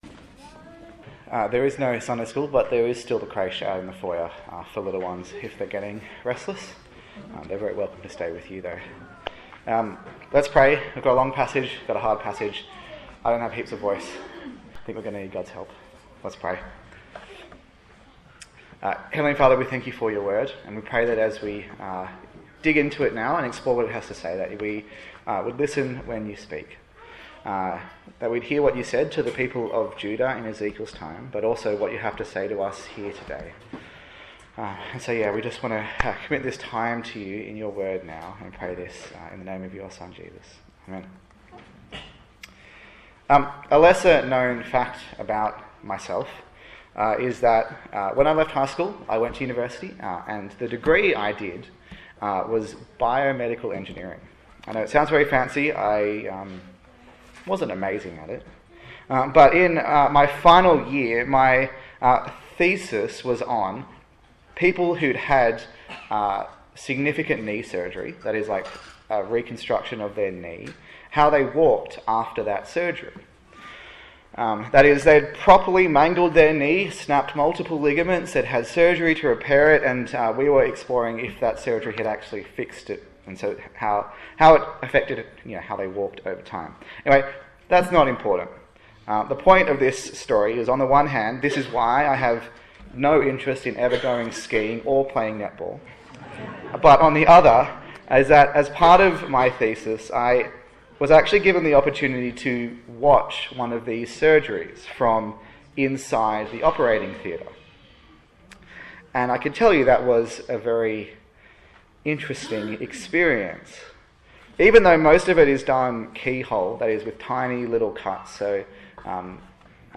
Ezekiel Passage: Ezekiel 12-17 Service Type: Morning Service